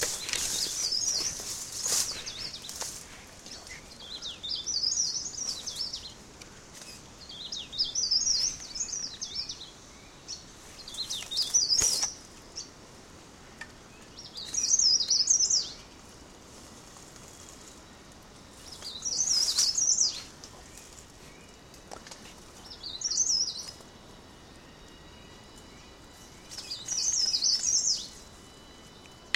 Grabación canto